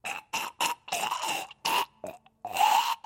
zombie_moan_6p1.mp3